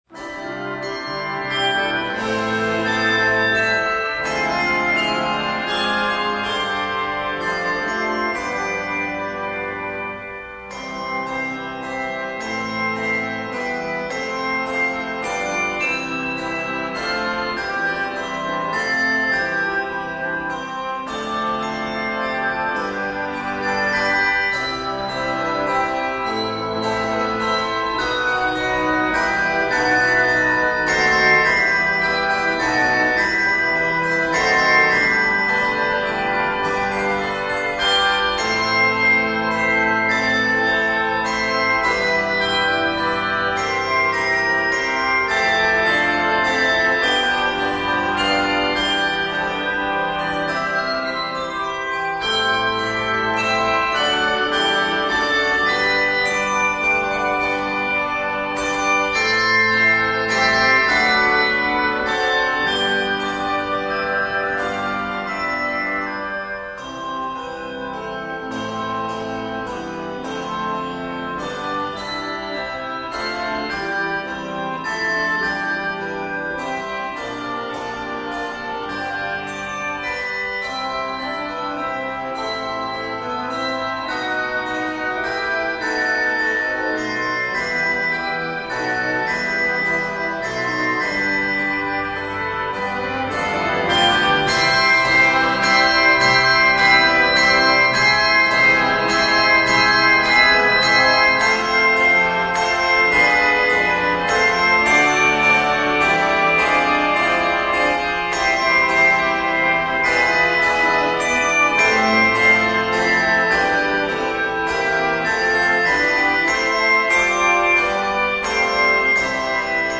it is scored in Eb Major.